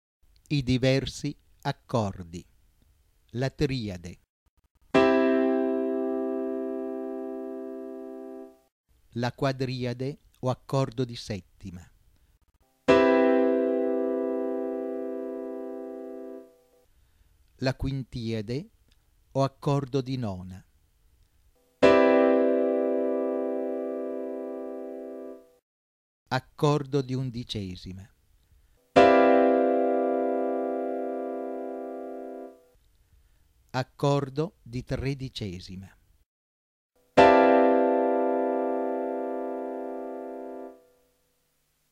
ascolto dei diversi accordi